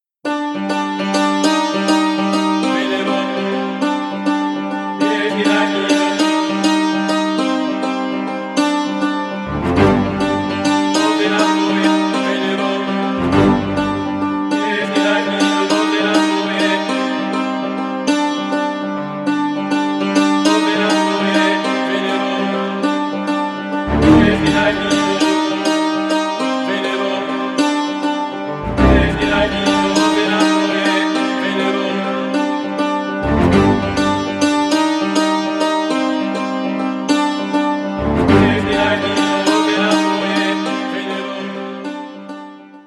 • Качество: 320, Stereo
саундтрек